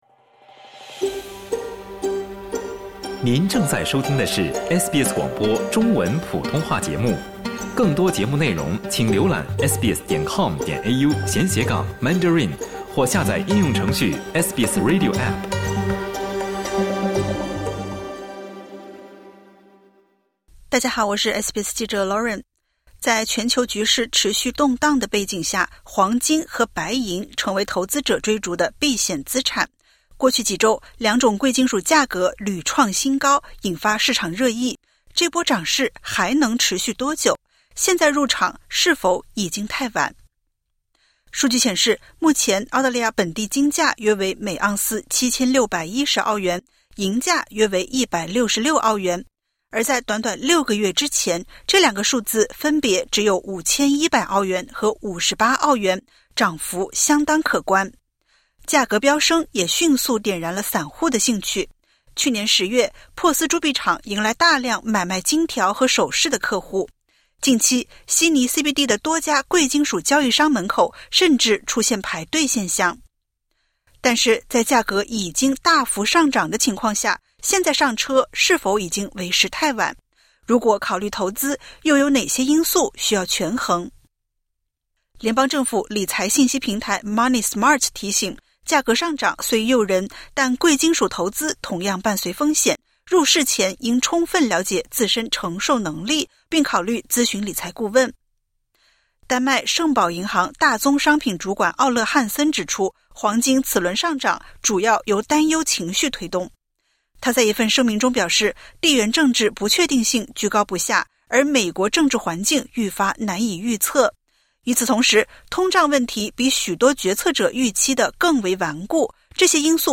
在全球局势动荡、通胀压力反复的背景下，专家们对这轮金银行情给出了不同判断。点击 ▶ 收听完整报道。